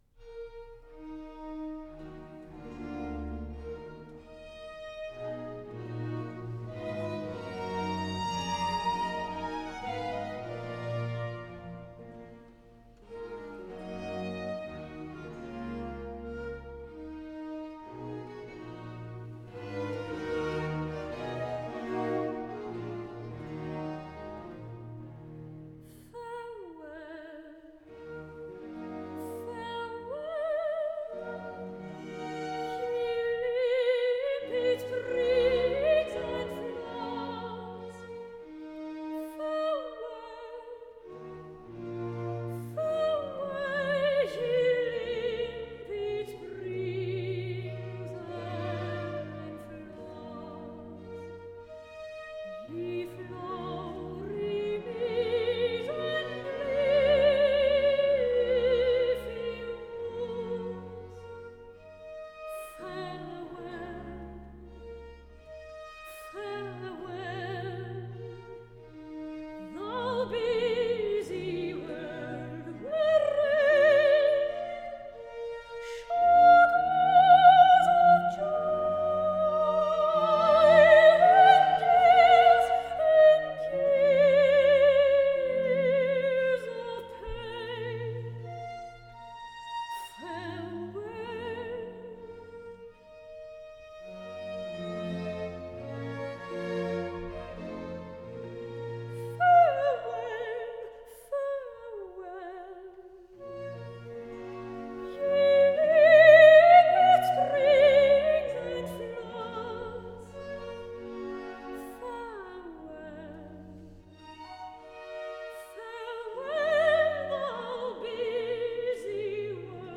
Air